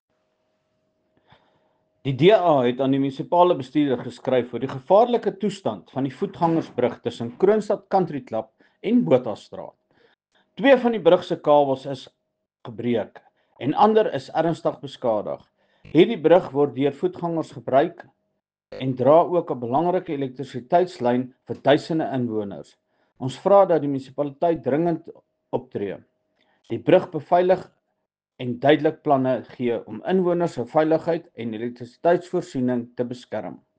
Afrikaans soundbites by Cllr Spaski Geldenhuis and Sesotho soundbite by Cllr Palesa Mpele.